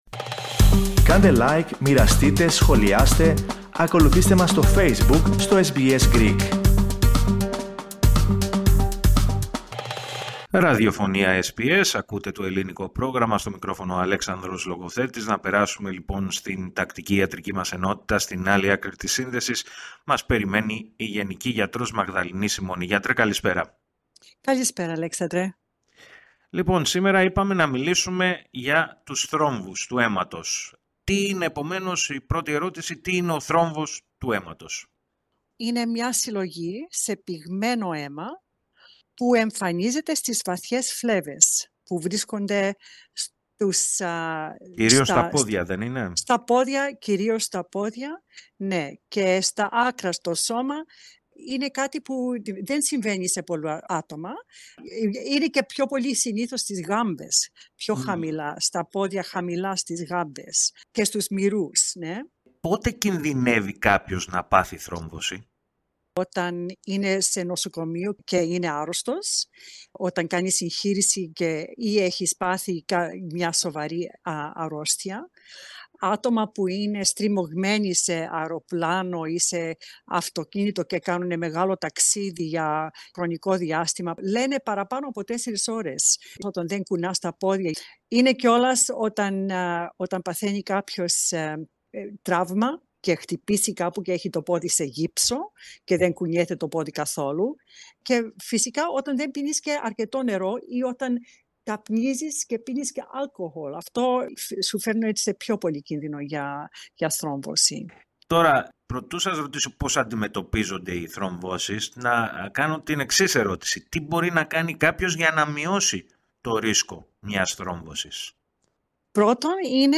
Η γενική γιατρός